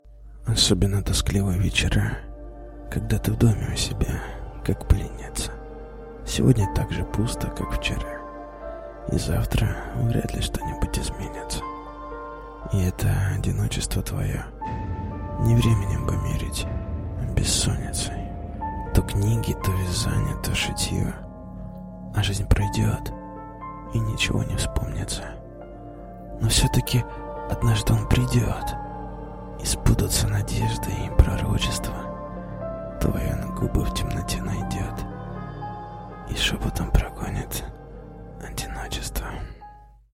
1. «(аудиостихи) Андрей Дементьев – Одиночество» /